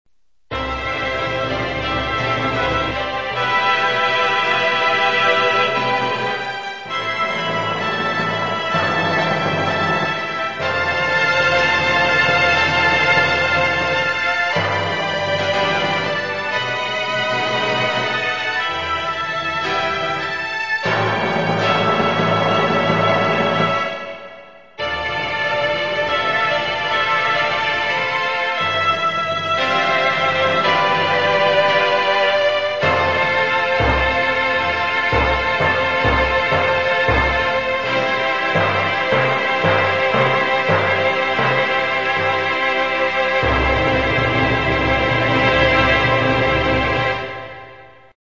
mp3-dwonloadで荘厳に、ケーキカットはティンパニーを効かせて